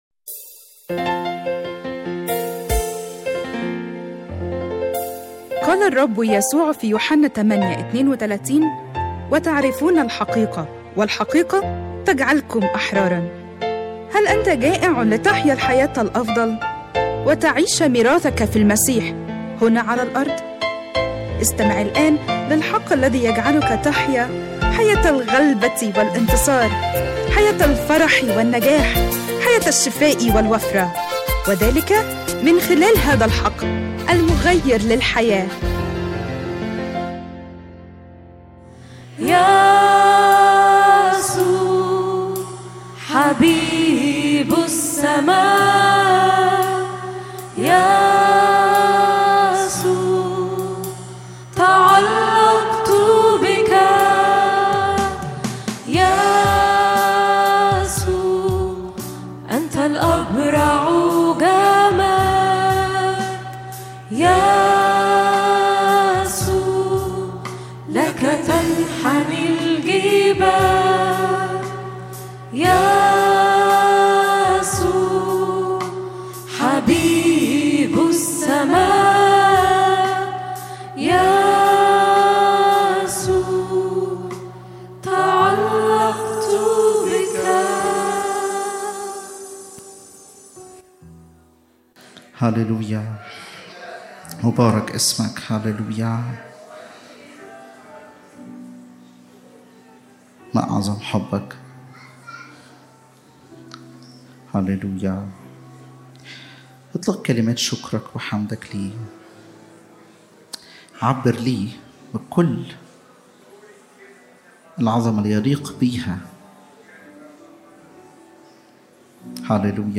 اجتماع الثلاثاء